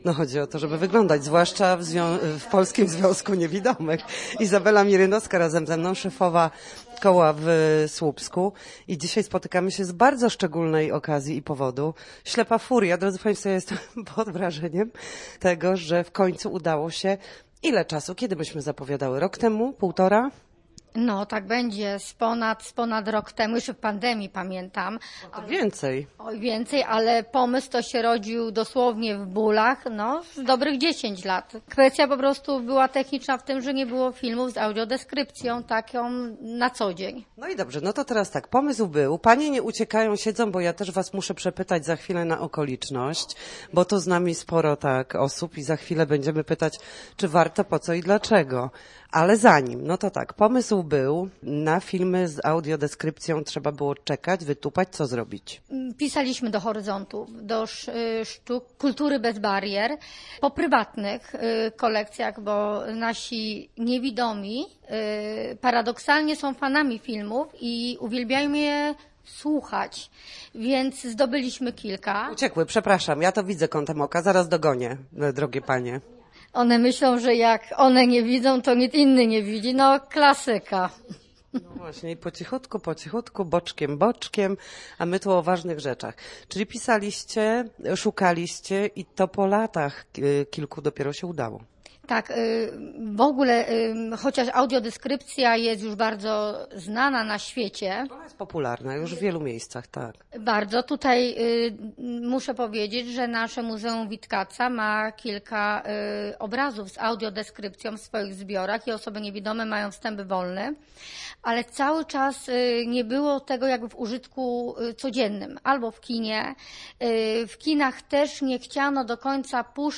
Filmów z audiodeskrypcją powinno być więcej – mówią w rozmowie z Radiem Gdańsk członkowie koła.